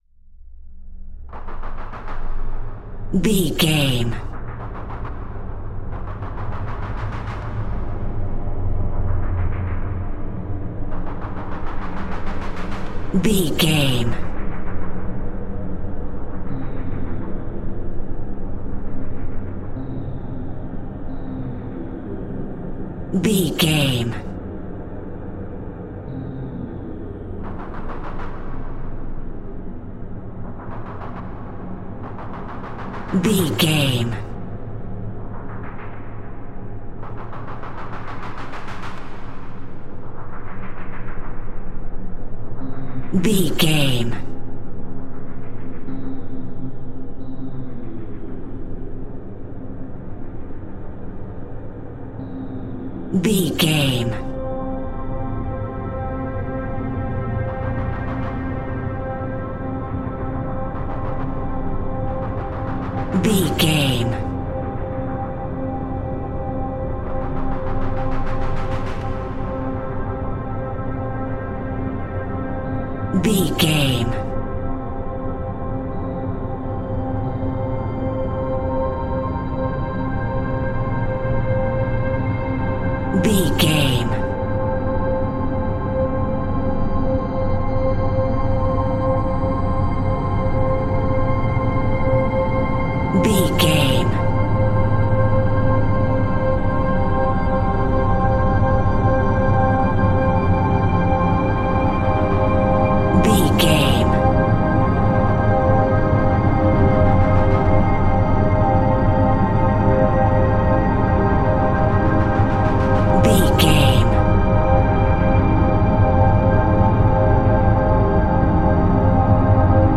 In-crescendo
Thriller
Aeolian/Minor
scary
ominous
dark
suspense
eerie
synthesiser
strings
percussion